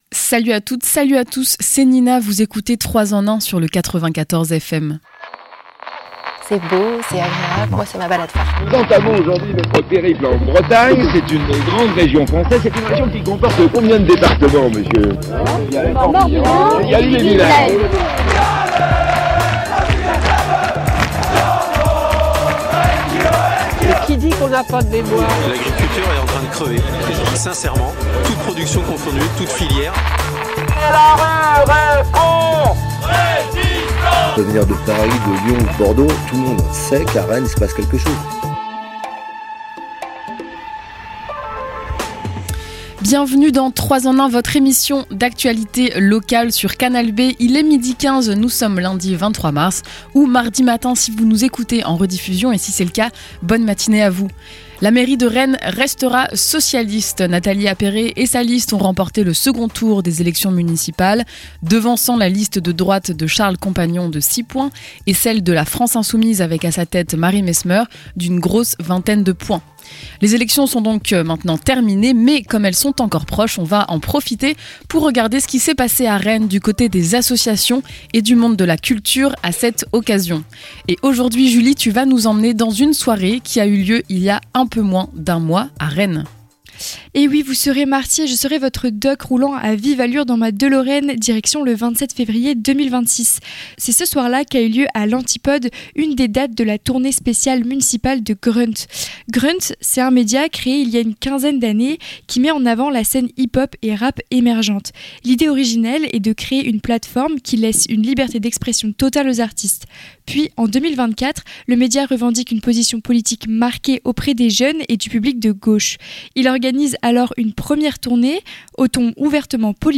Dans cette chronique nous revenons sur la raison de leur présence à cet évènement culturel. Et enfin cette soirée c'était aussi pour rassembler des spectateurs , parfois politiques, toujours amateurs de rap, vous pourrez entendre leurs impressions en fin d'émission.